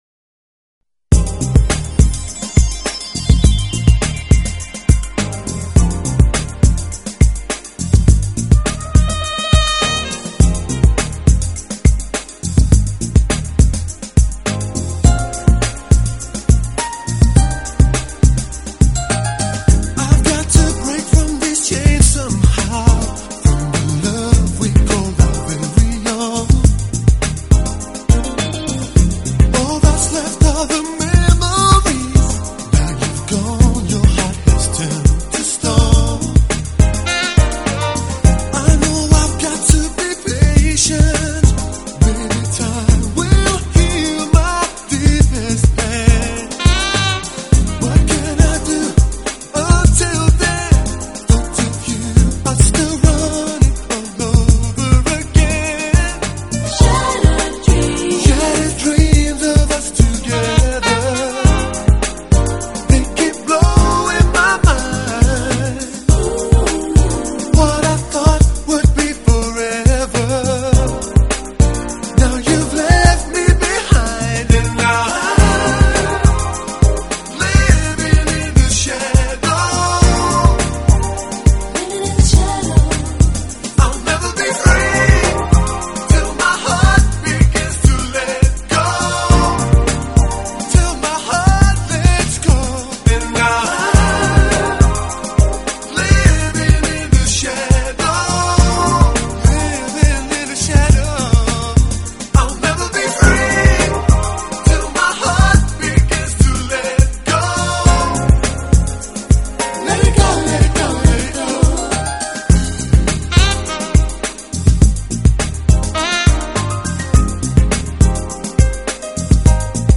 始录制唱片，由于善于把握时尚元素，将Smooth Jazz与电子、舞曲风格完美结
旋律轻柔流畅，器乐创新搭配，节奏舒缓时尚，魅力女
Jazz如同和煦清爽的凉风与清凉透心的泉水流淌全身，令人舒适之极。